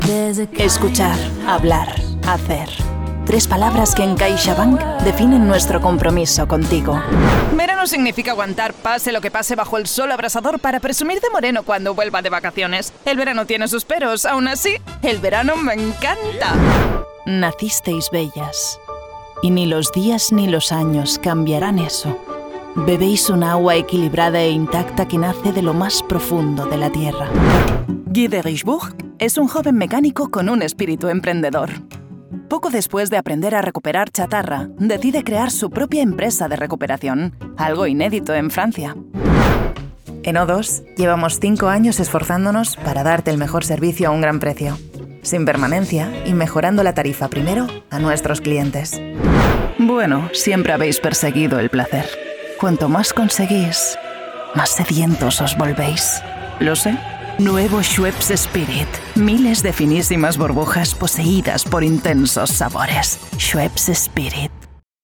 Voice Artists - Spanish
ES REEL Publicidad 2024.mp3
All our artists are equipped with a professional home studio, ensuring high-quality recordings while saving you both time and money.